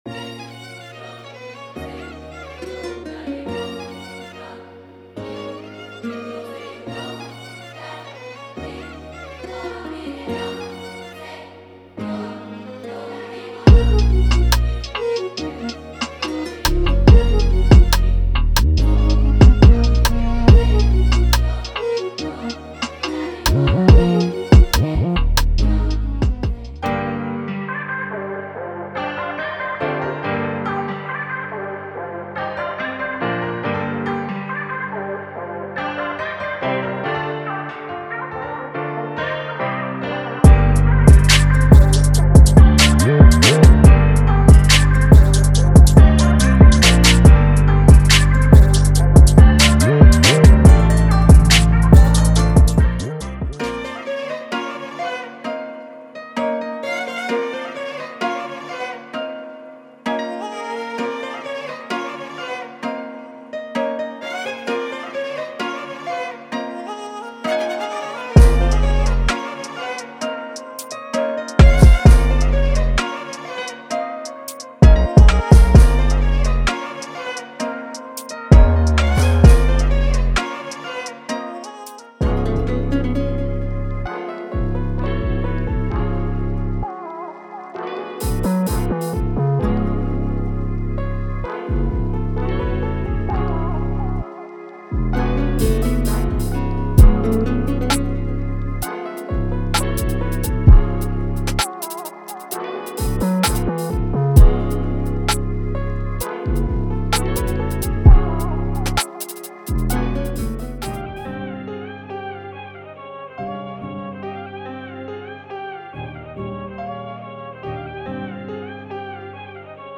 • Melodic Drill Sounds